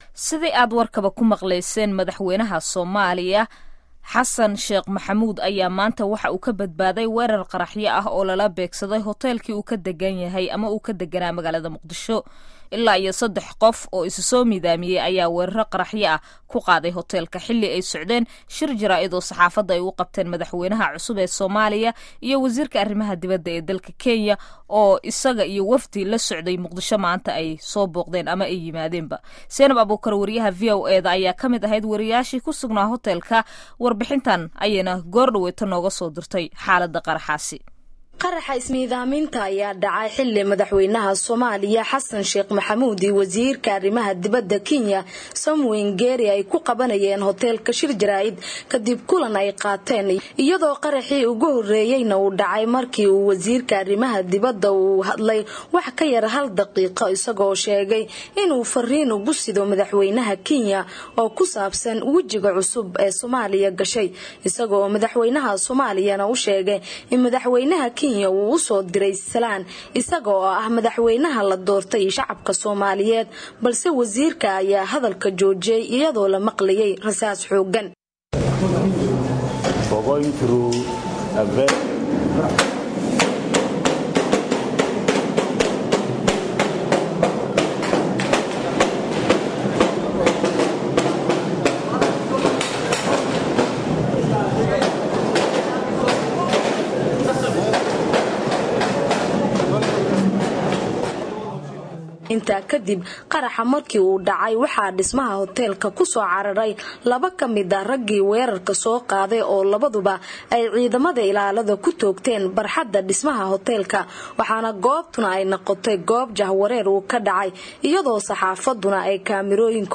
Halkan ka dhageyso warbixin iyo wareysiyo ku saabsan qaraxyada